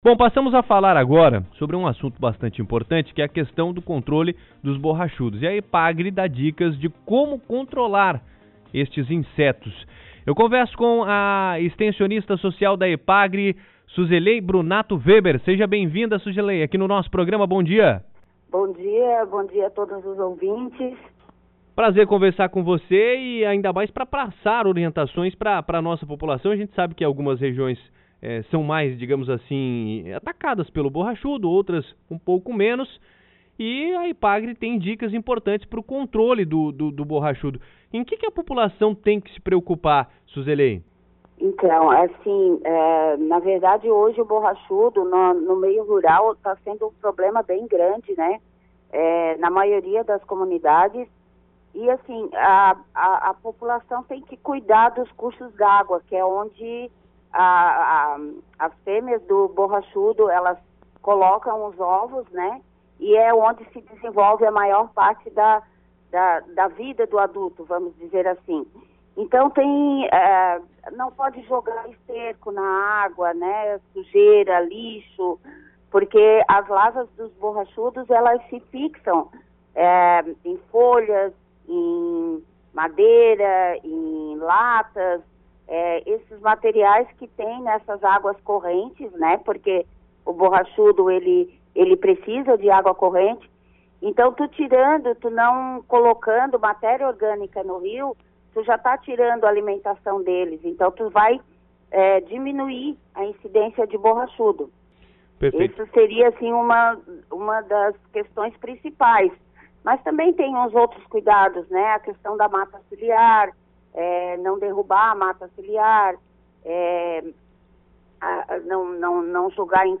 Confira na íntegra a entrevista completa com a especialista e saiba como evitar os borrachudos na região: